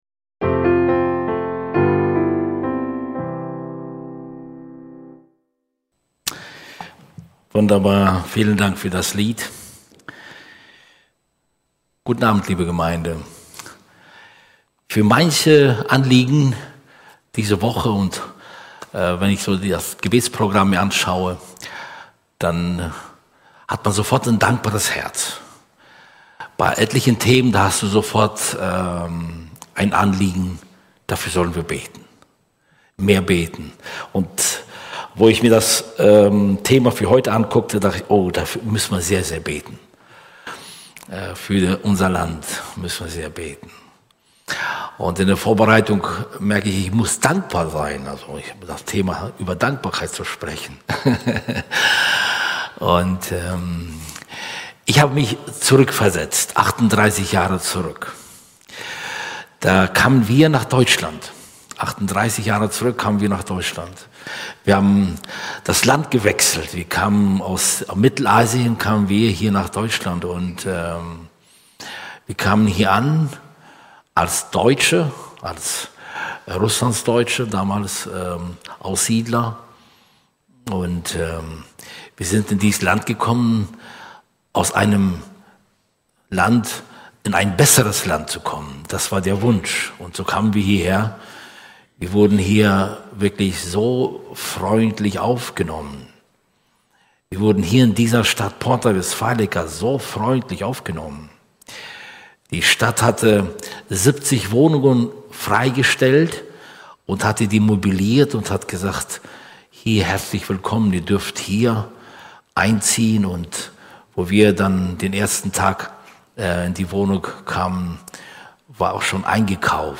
Unser_Land_-_5-_Gebetsabend.mp3